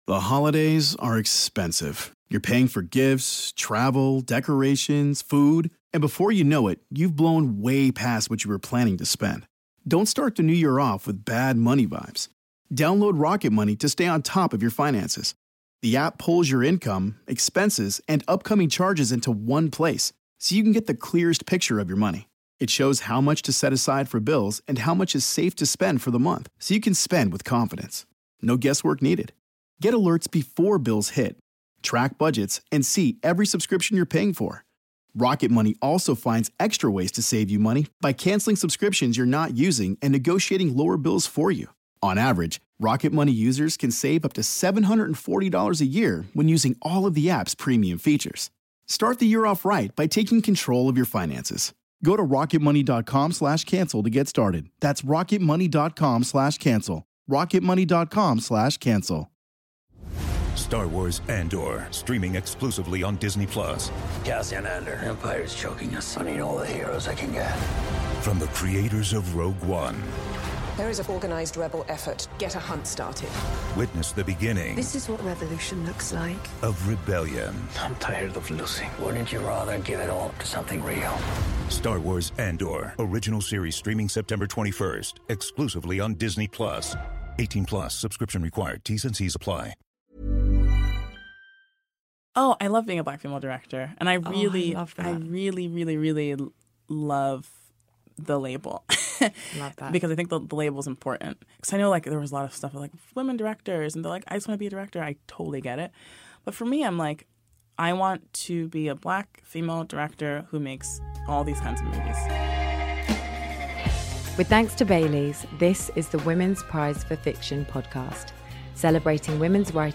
Film director Nia DaCosta chats to Zawe Ashton about directing the new Marvel film - The Marvels and why she’s proud of the label ‘black, female director’.